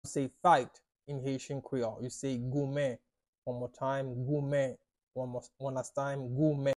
“Fight” in Haitian Creole – “Goumen” pronunciation by a native Haitian tutor
“Goumen” Pronunciation in Haitian Creole by a native Haitian can be heard in the audio here or in the video below:
How-to-say-Fight-in-Haitian-Creole-–-Goumen-pronunciation-by-a-native-Haitian-tutor.mp3